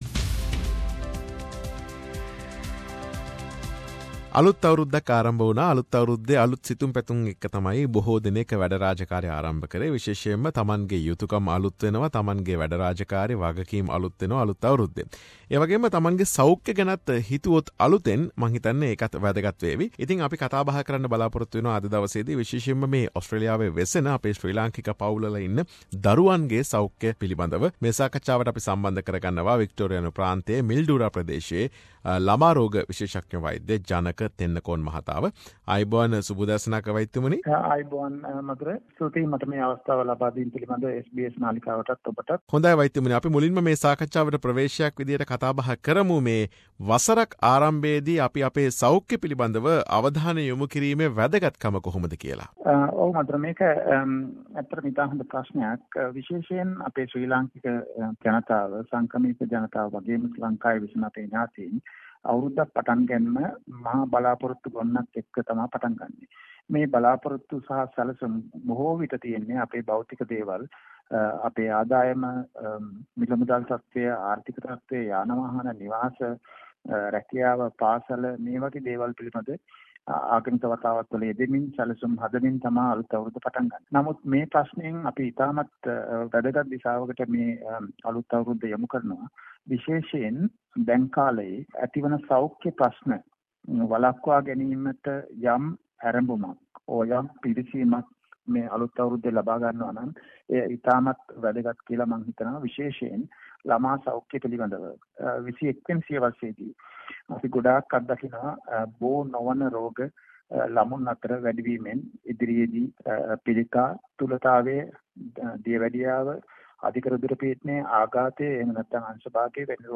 SBS සිංහල සිදු කළ සාකච්ඡාව.